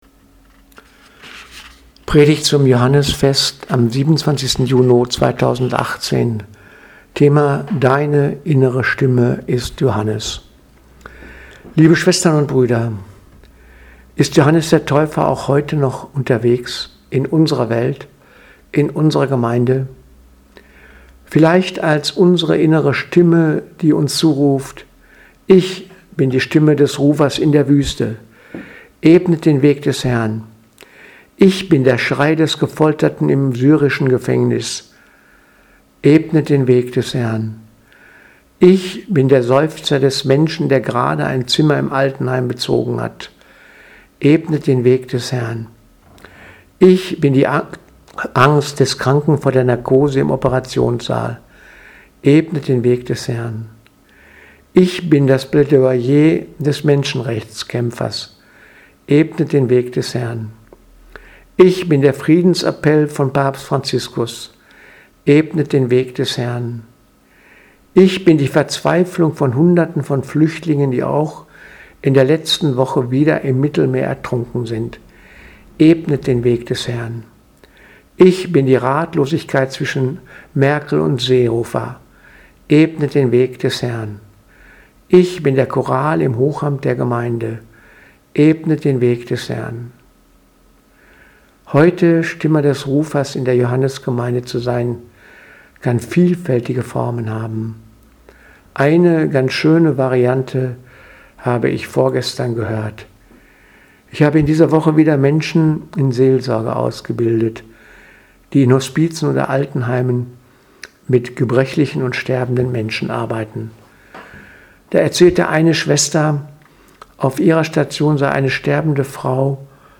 Predigt vom 24.06.2018 Johannesfest